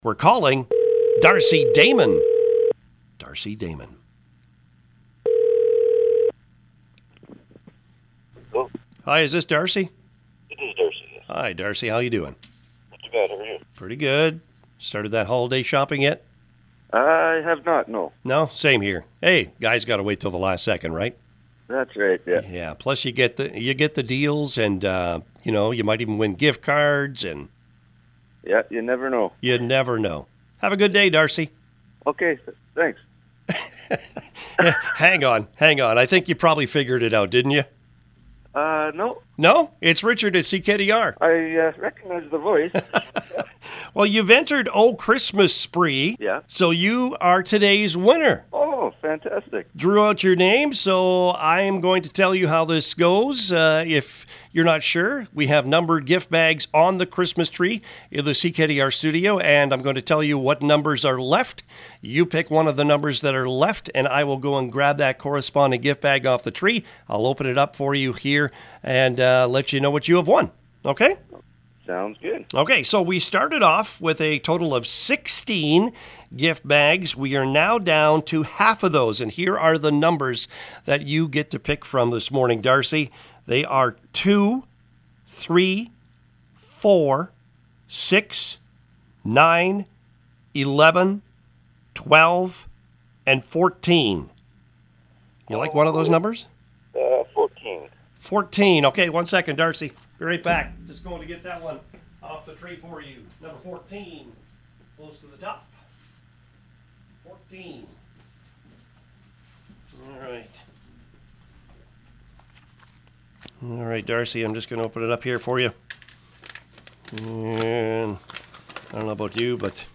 Here’s Friday’s winning call……